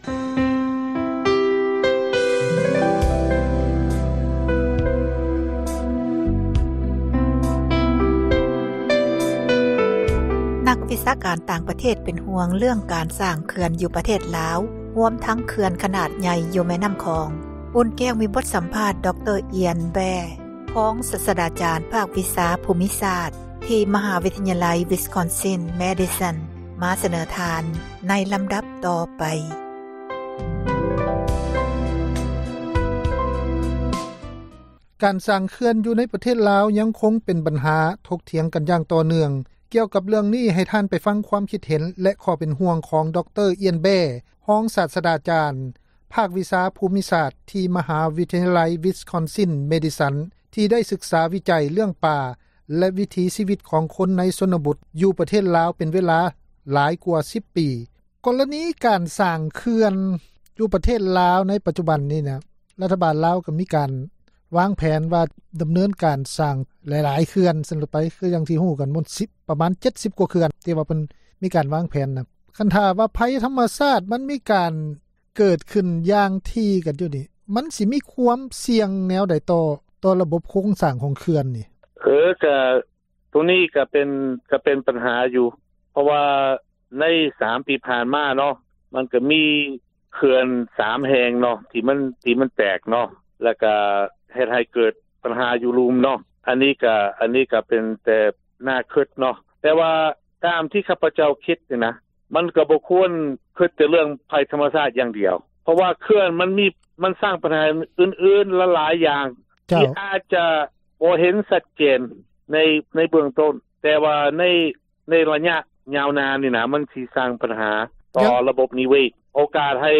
ມີບົດສຳພາດ